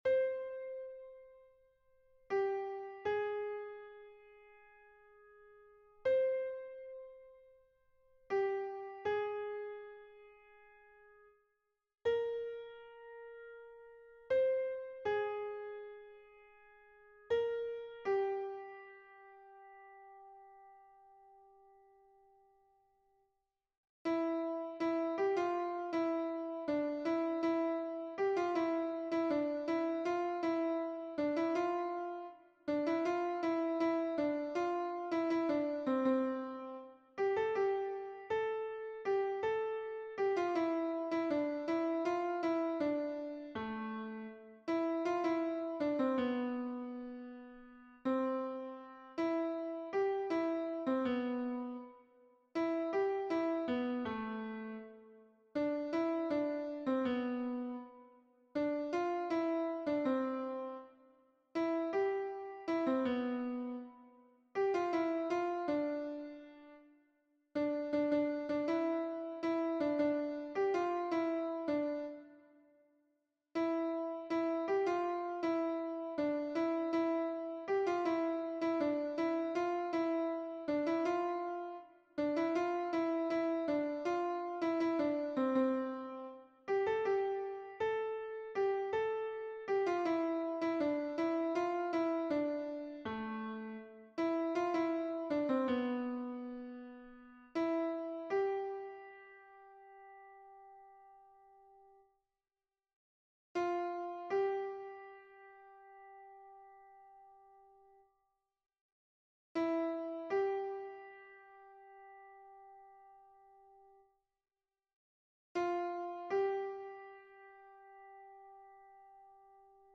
MP3 version piano (les audios peuvent être téléchargés)
Voix 1 : soprano et ténor